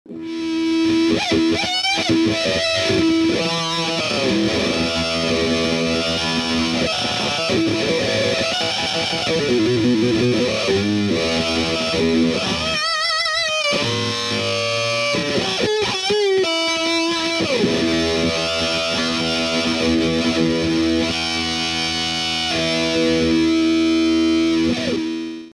クラシックワウサウンド、スタンダードワウサウンド、ベースと、3モード切り替え可能で多彩な表現力を誇ります！